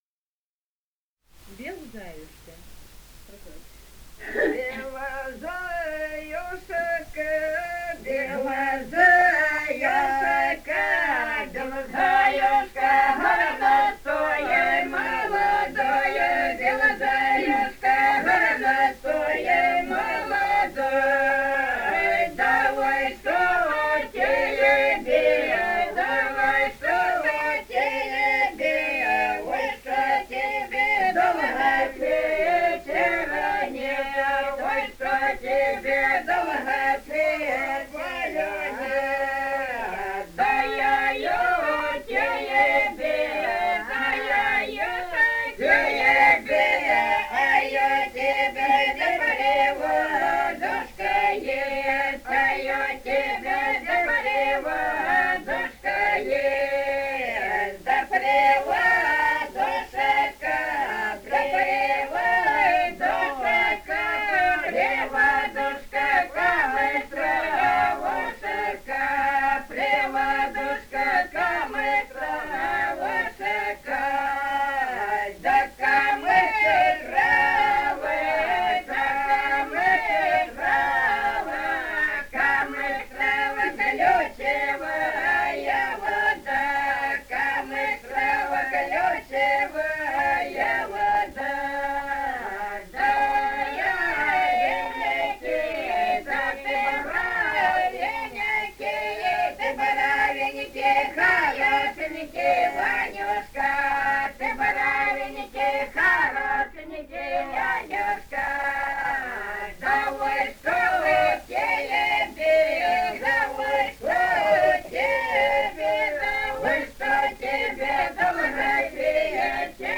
Этномузыкологические исследования и полевые материалы 205. «Бел заюшка» («на сводах»).
Ростовская область, г. Белая Калитва, 1966 г. И0940-11